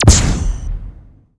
fire_bolt_long.wav